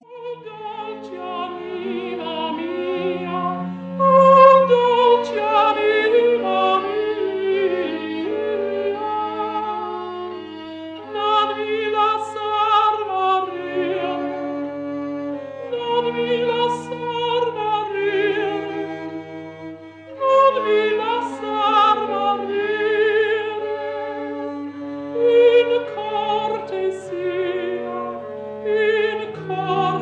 counter-tenor
viol
Recorded in Abbey Road studio 3, London, 3 April 1950